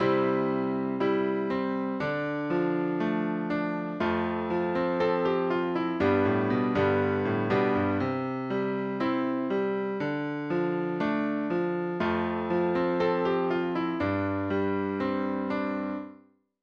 Als Beispiel für ein modernes Würfelspiel enthält tonica fugata den Würfelstil "Lift-Off Chord Progression", der achttaktige Klaviersätze erzeugt, die auf der für Pop-Songs typischen Akkordfolge C - Dm - F - G aufbauen.